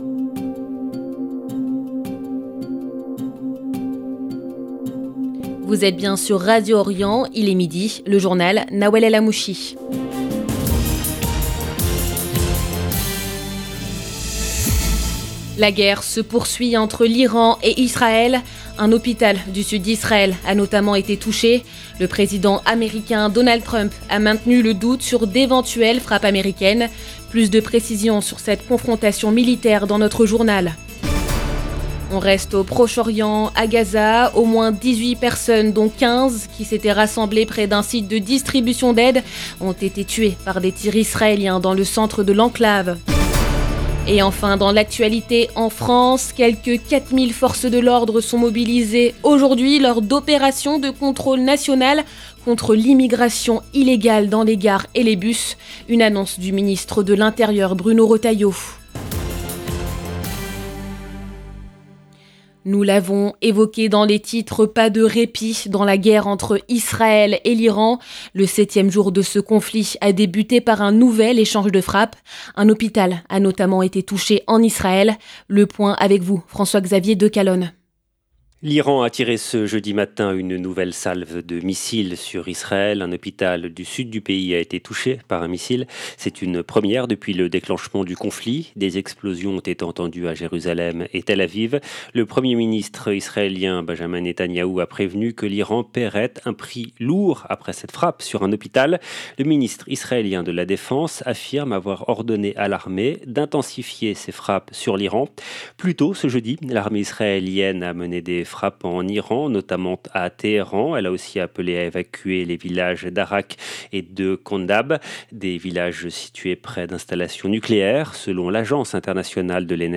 Journal de midi du jeudi 19 juin 2025